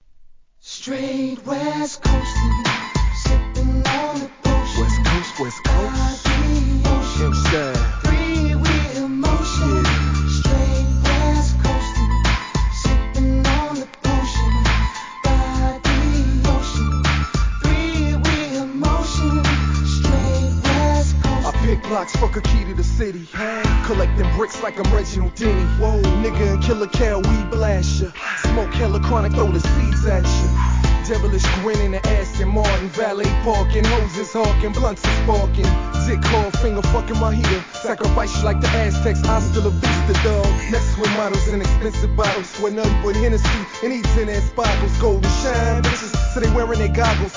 G-RAP/WEST COAST/SOUTH
2004年、フックにヴォーカルを絡めた哀愁G!!